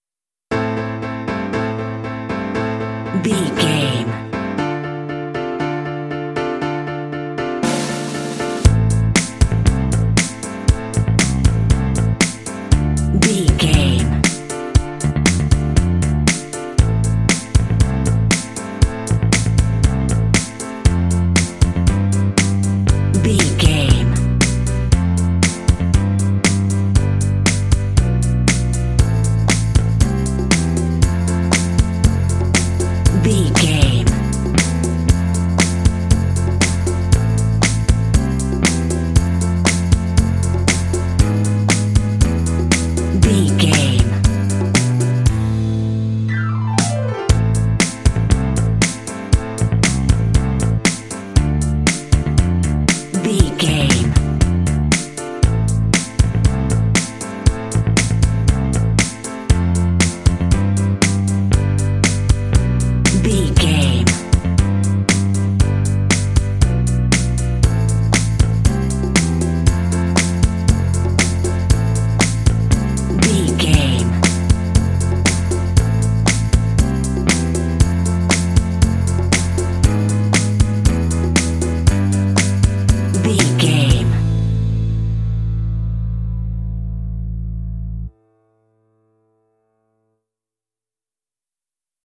Aeolian/Minor
bouncy
happy
groovy
bright
piano
bass guitar
drums
conga
rock
contemporary underscore